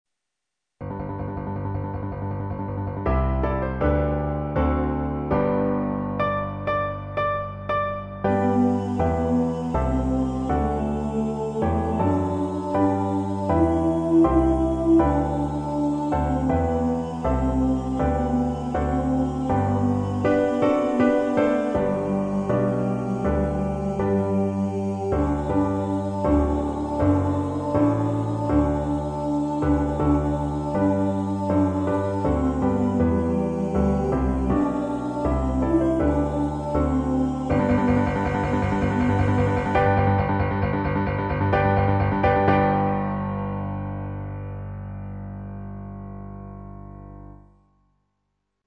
If you would like to practice up on a vocal part, here are some part recordings which may assist you.